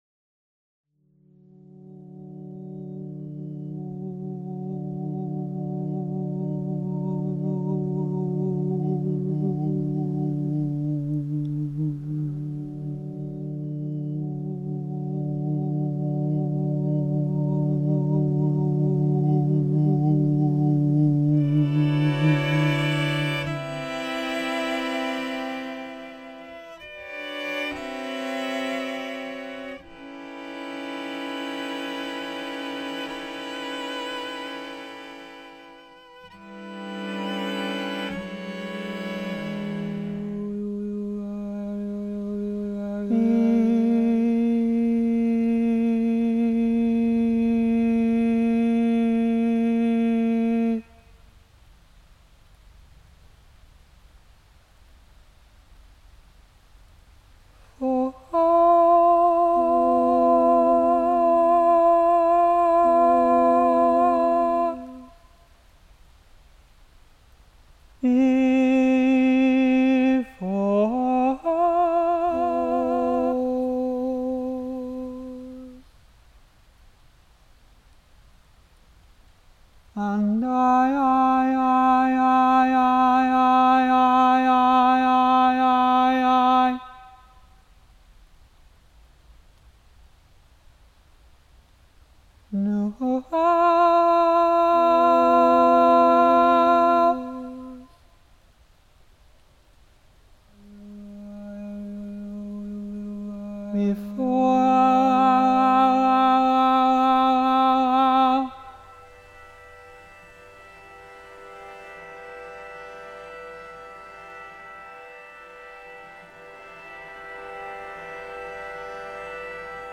cellist
layered with his own voice that sits somewhere between language and song